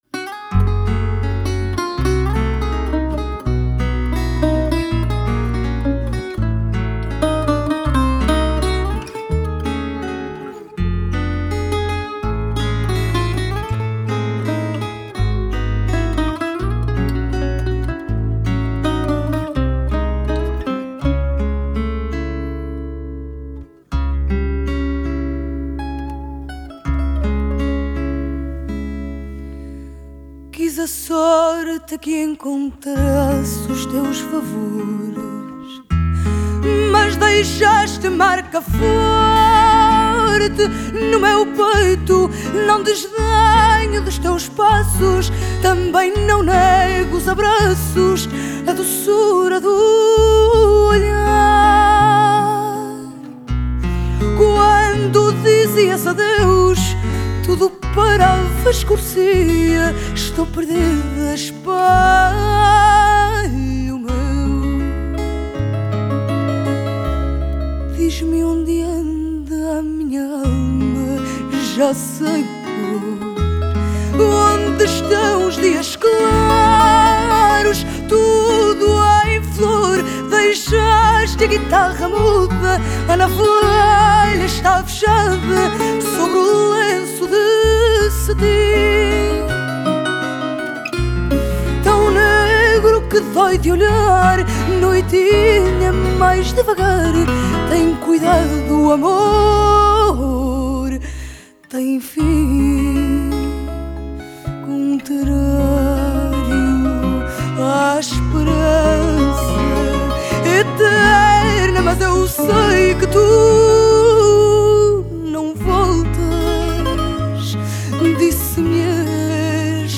Genre: Fado, Folk, Portuguese music